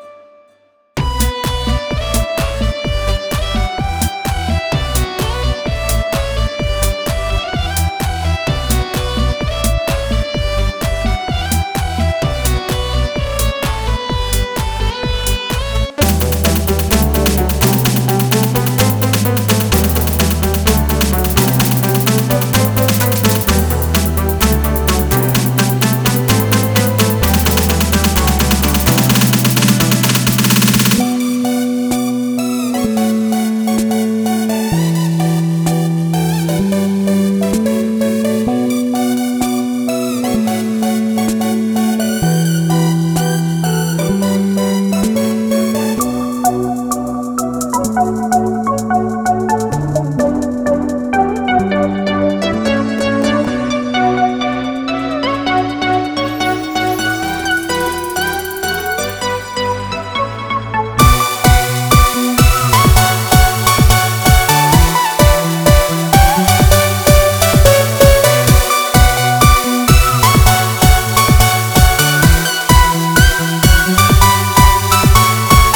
ממליץ בקטע הראשון לשנות סאונד לא קשור לזה גיטרה חשמלית
לא אהבתי את הסאונד של הטראק הראשון, אבל השאר סבבה